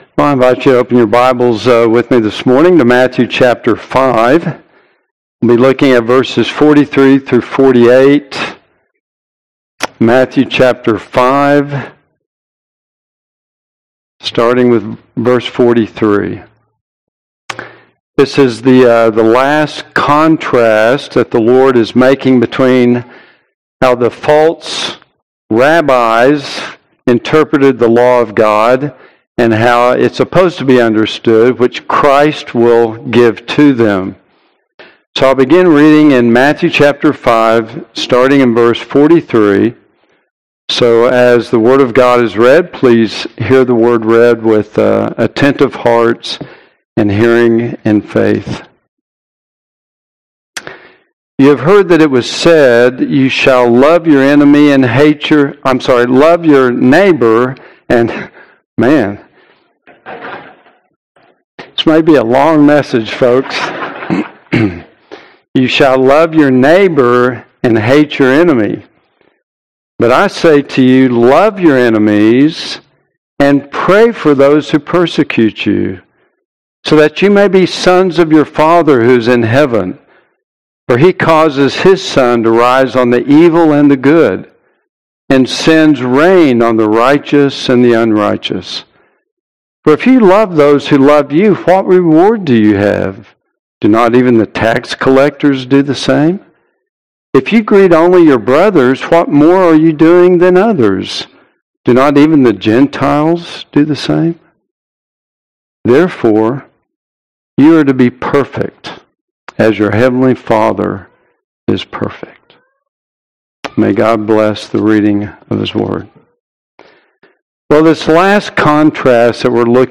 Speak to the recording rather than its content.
Passage: Matthew. 5:43-48 Service Type: Sunday AM